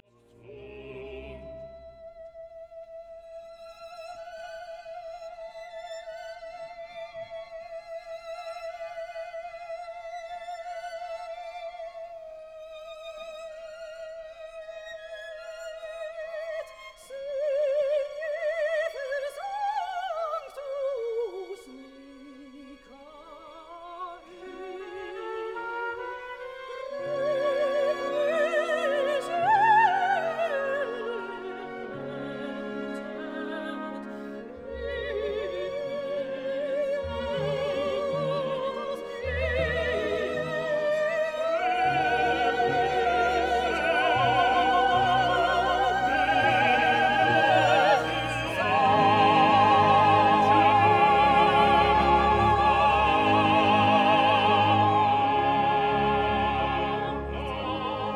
Live-Mitschnitte von Konzerten
Konzerthaus am Gendarmenmarkt am 9.